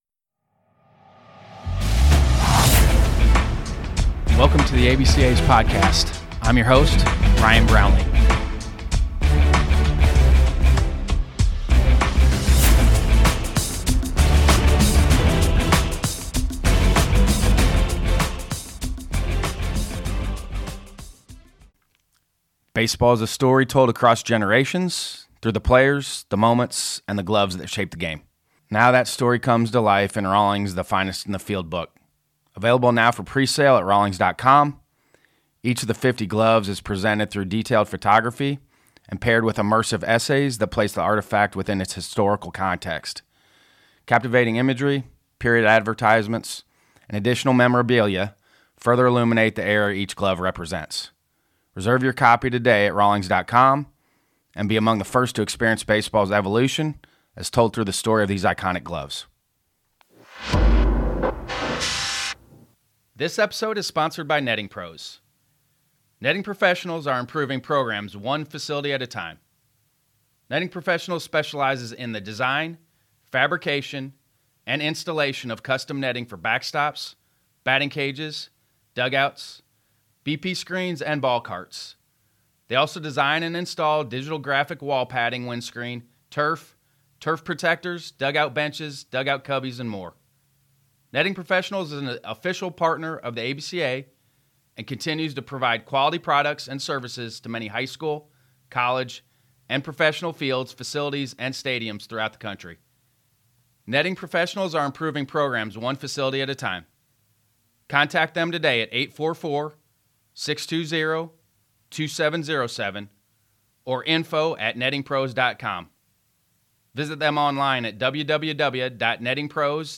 We made our annual trip to Cary for the USA Baseball National High School Invitational (NHSI) to sit down with some of our ABCA member coaches competing on one of the biggest stages in high school baseball.